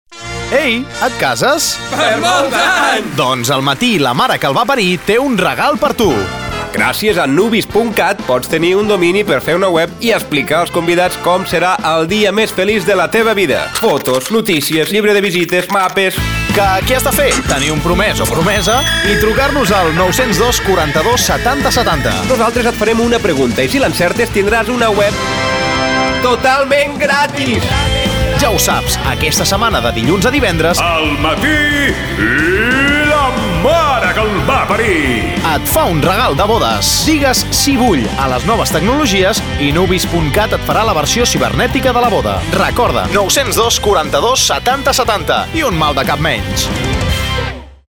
A continuació us presentem les falques de publicitat que s’han emès a ràdio Flaix Bac: